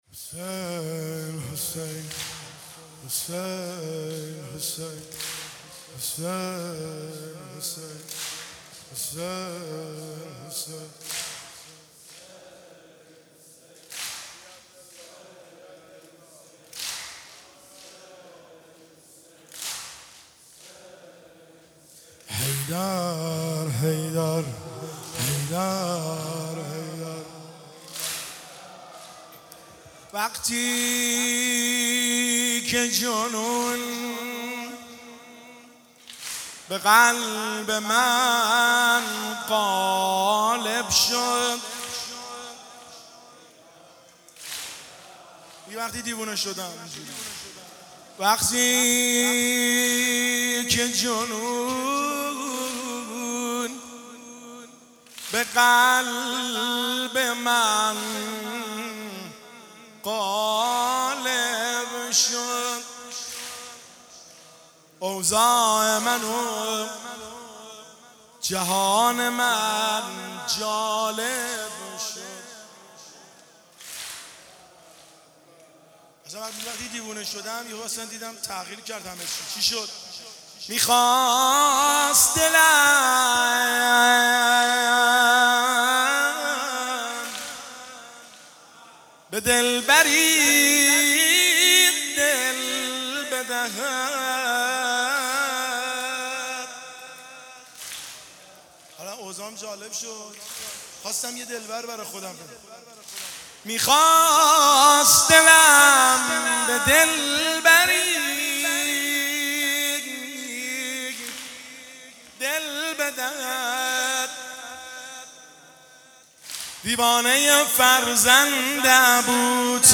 جلسه هفتگی 31 خرداد